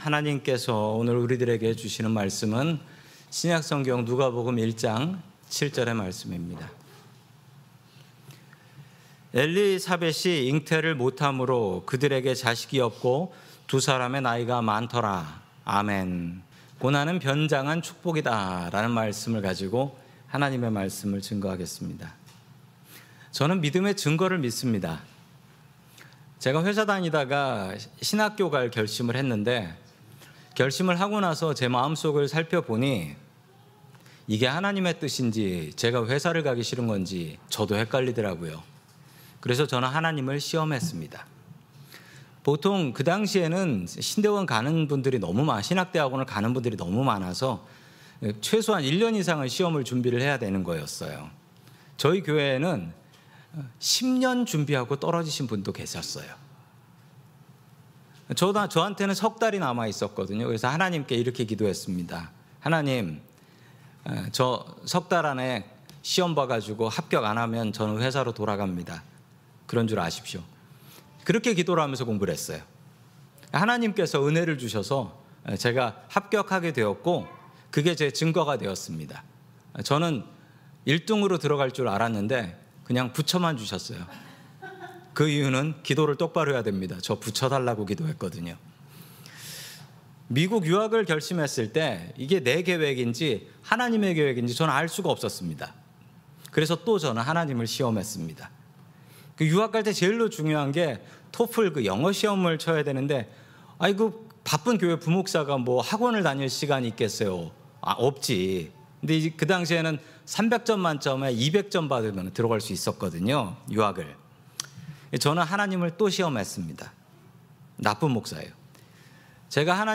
샌프란시스코 은혜장로교회 설교방송